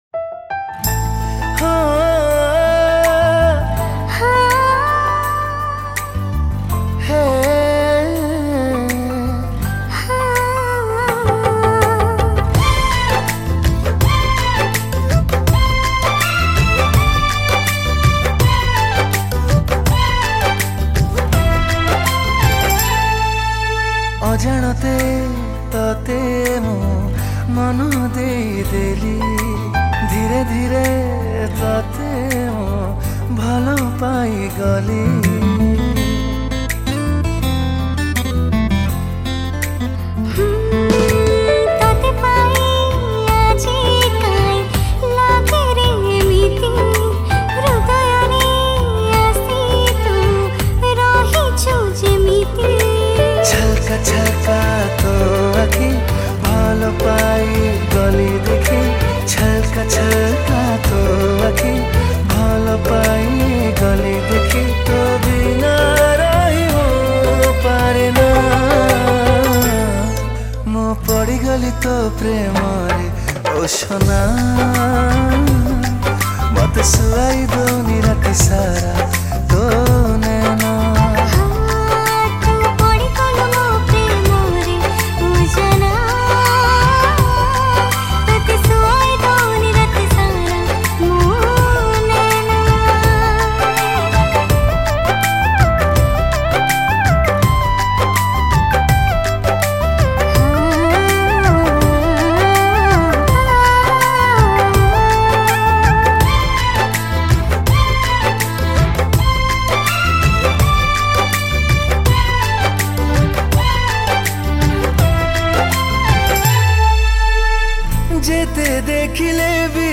Song Type :Romantic Song